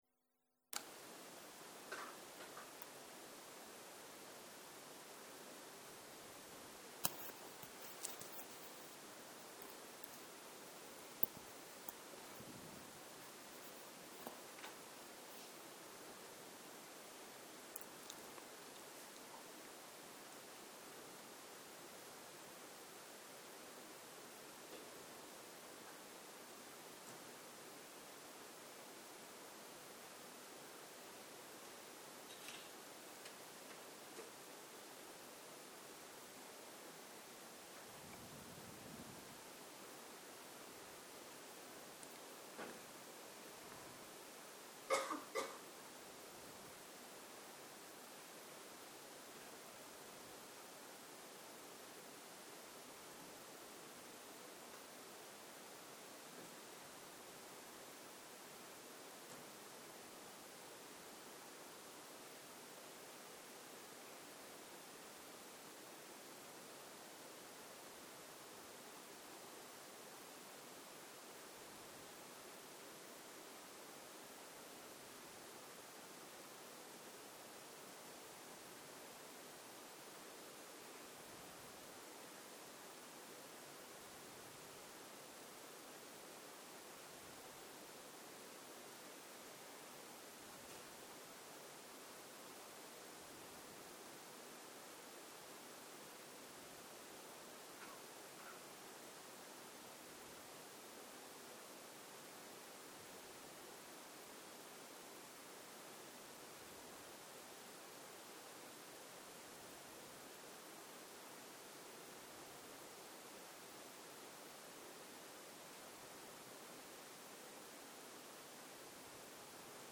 ערב - מדיטציה מונחית - התבוננות בגוף דרך היסודות
Dharma type: Guided meditation שפת ההקלטה